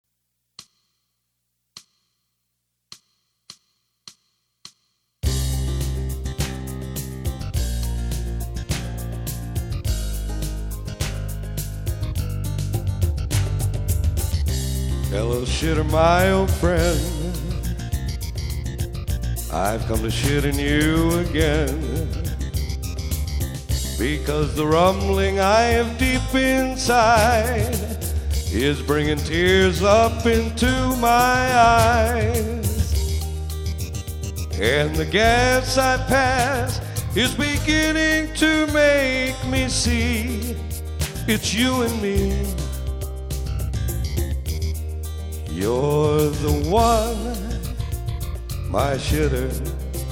singing toilet songs